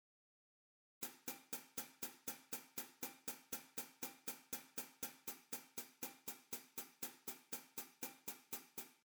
↓の例は、前半＝ベロシティが均一（いわゆるベタ打ち）、後半＝ベロシティの変化ありです。
ベロシティの有無の比較